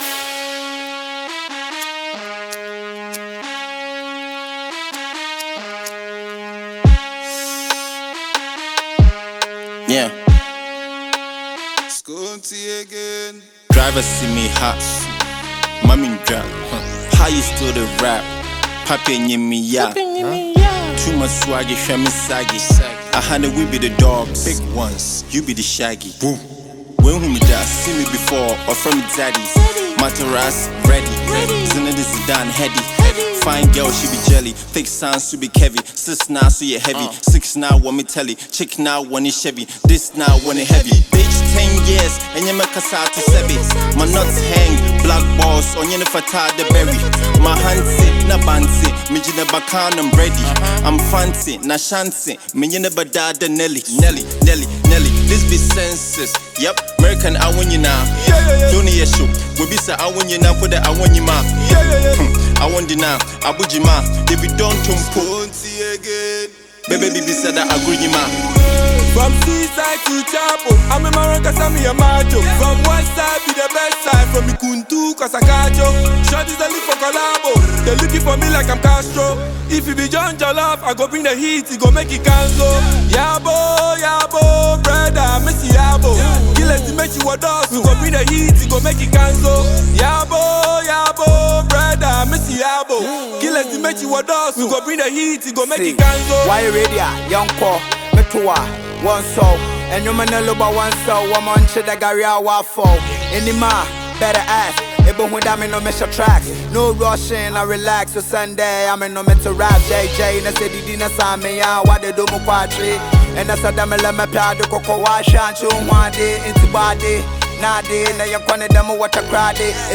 Ghana Music Music
Sensational Ghanaian rapper